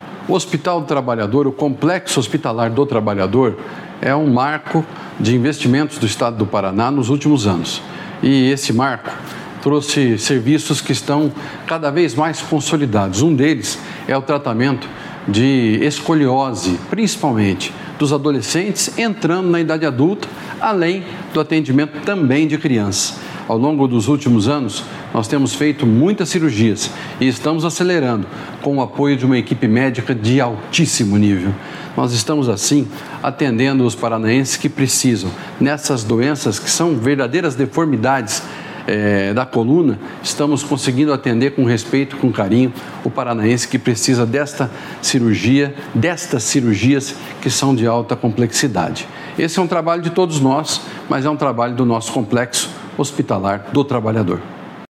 Sonora do secretário da Saúde, Beto Preto, sobre as cirurgias na coluna no Complexo Hospitalar do Trabalhador